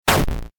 bigshot.mp3